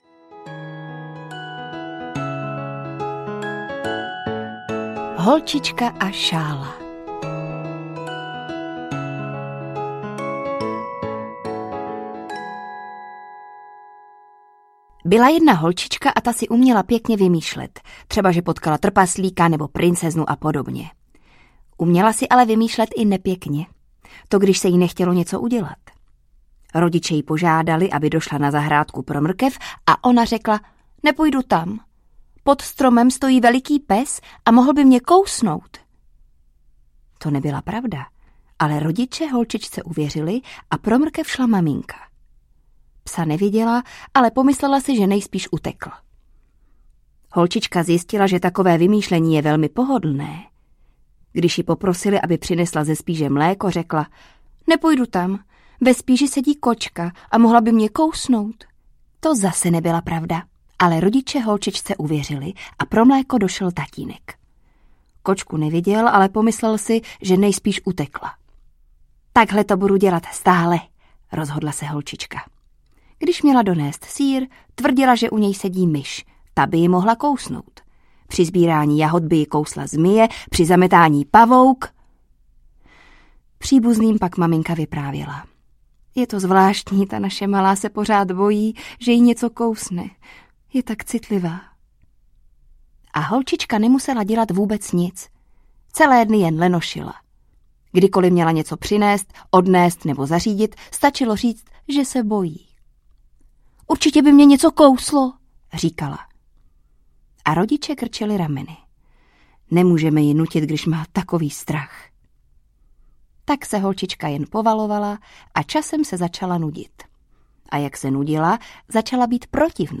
Zlobilky audiokniha
Ukázka z knihy